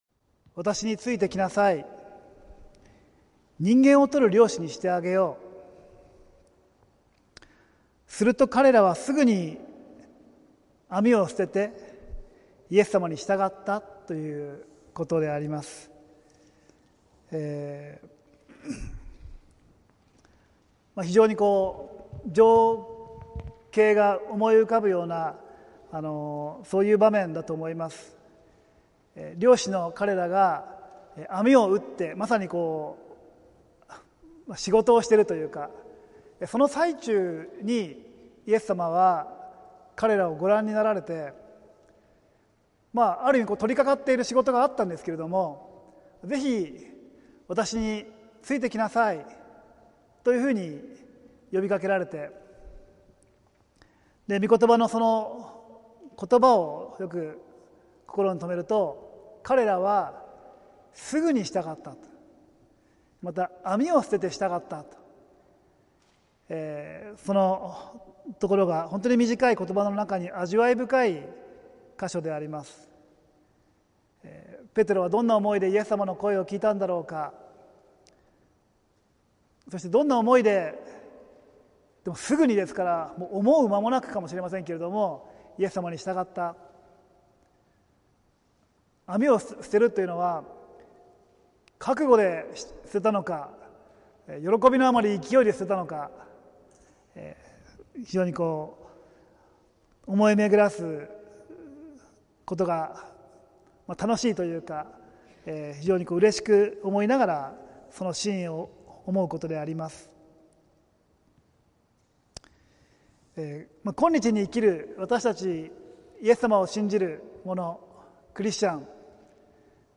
浦和福音自由教会(さいたま市浦和区)の聖日礼拝(2023年9月3日)「自分の十字架を負って｣(週報とライブ/動画/音声配信)